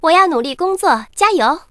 文件 文件历史 文件用途 全域文件用途 Daphne_amb_01.ogg （Ogg Vorbis声音文件，长度0.0秒，0 bps，文件大小：24 KB） 源地址:游戏语音 文件历史 点击某个日期/时间查看对应时刻的文件。